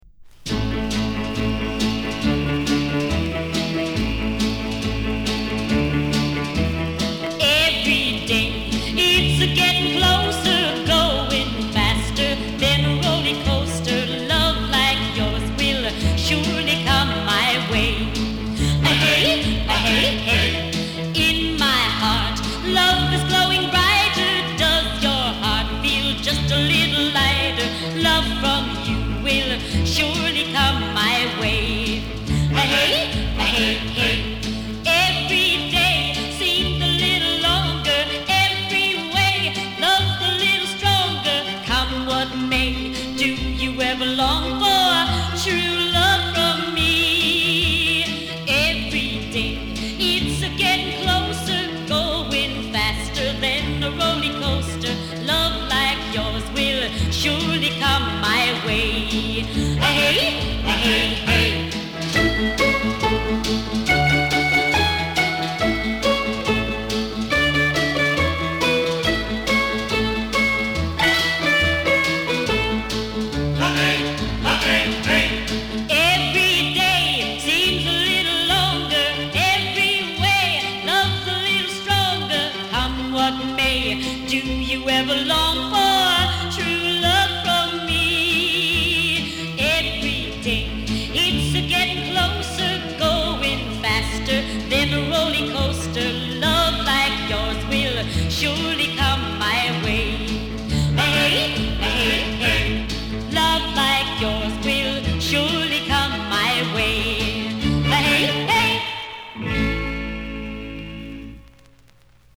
珍しいフィメイル・ヴォーカル・ヴァージョンであり、印象がガラリと変わって非常に新鮮だ。